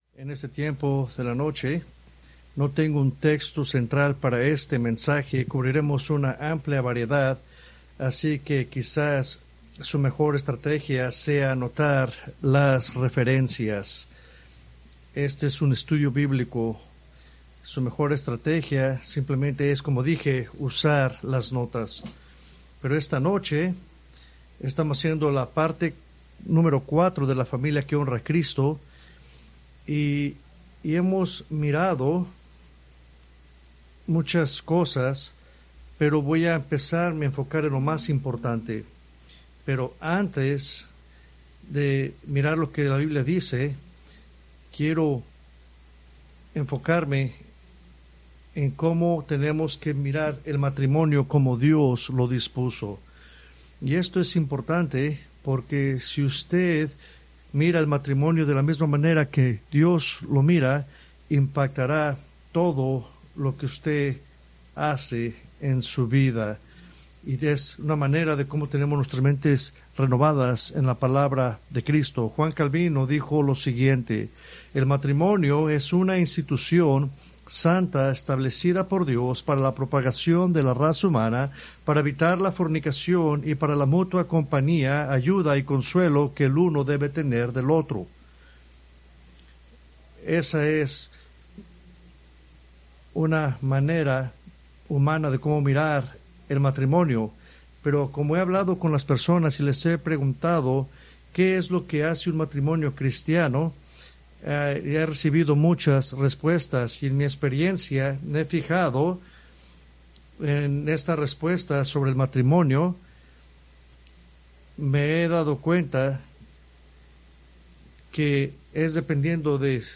Preached July 6, 2025 from Escrituras seleccionadas